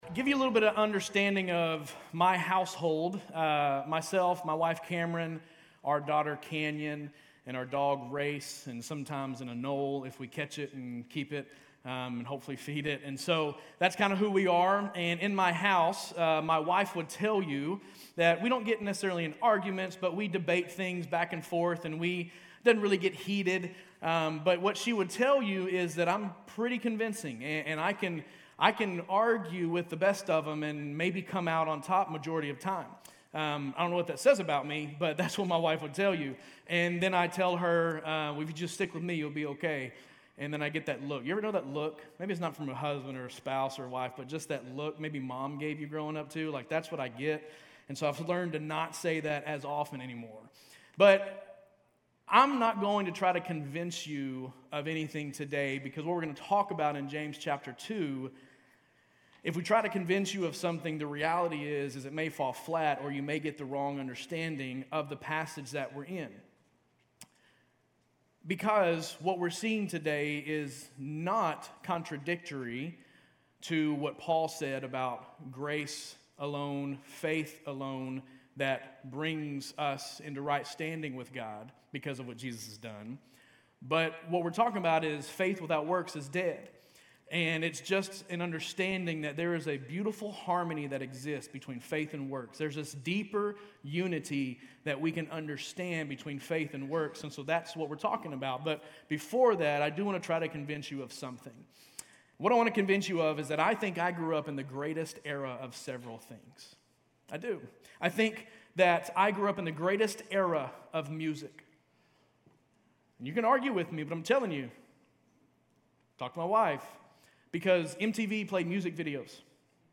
GCC-UB-July-2-Sermon.mp3